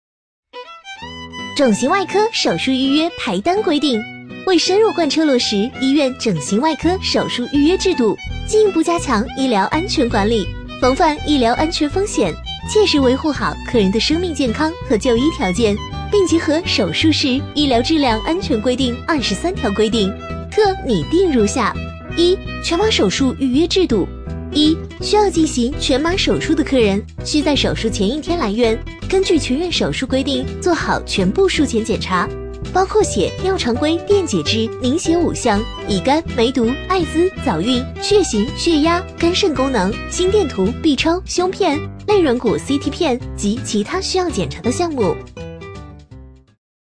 A类女50
【女50号MG、飞碟说】-整形外科
【女50号MG、飞碟说】-整形外科.mp3